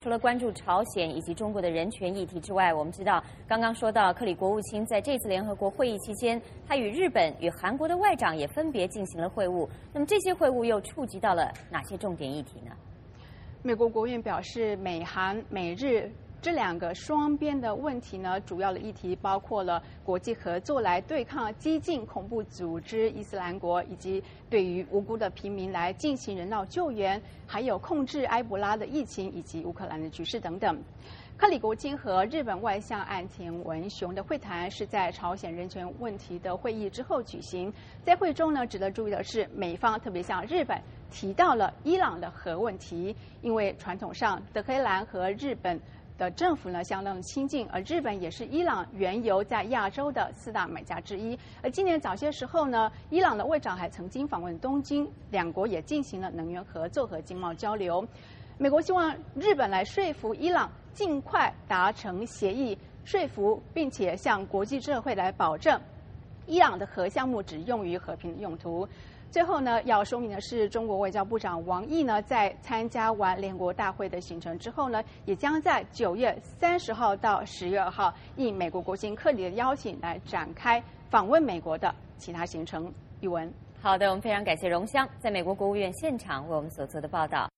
VOA连线：美日韩外长会晤 深化国际合作对抗“伊斯兰国”